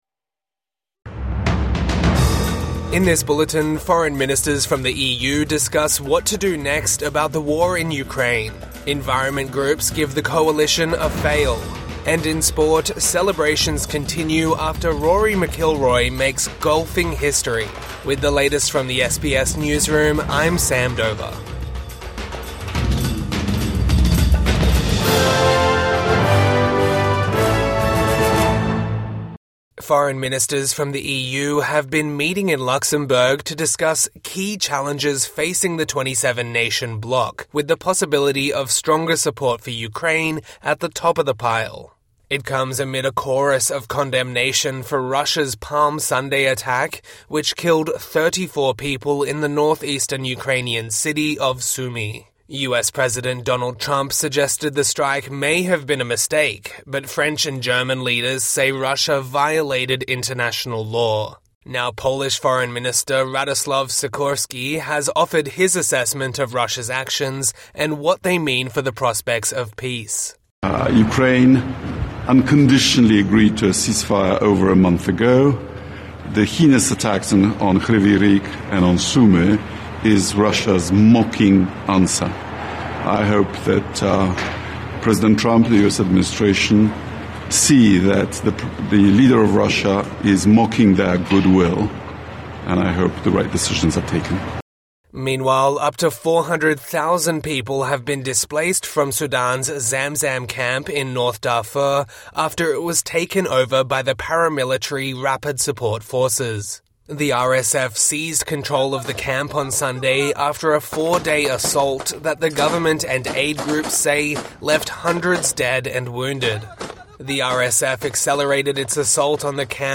EU leaders condemn Russia following Palm Sunday attack | Morning News Bulletin 15 April 2025